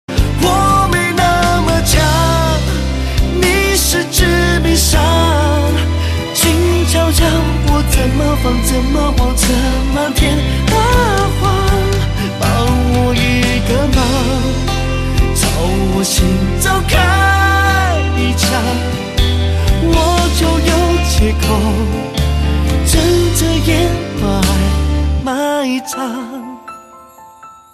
M4R铃声, MP3铃声, 华语歌曲 36 首发日期：2018-05-15 01:49 星期二